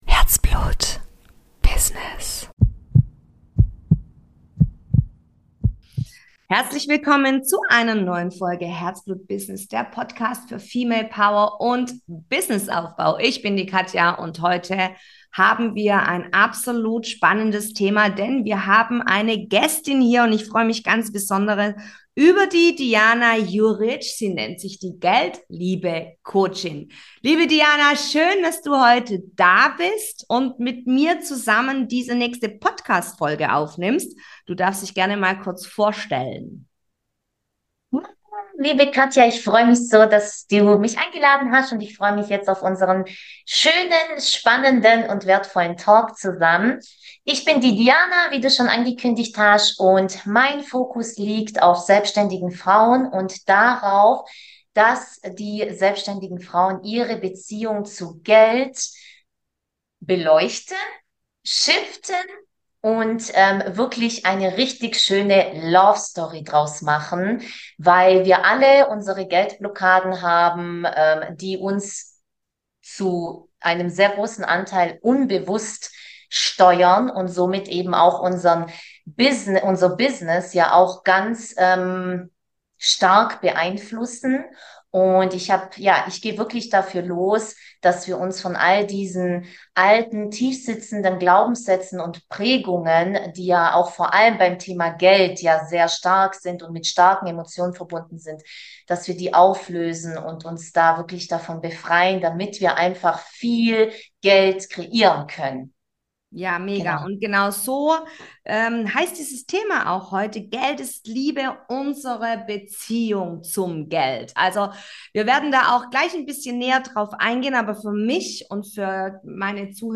#60 Geld ist Liebe. Unsere Beziehung zu Geld - Interview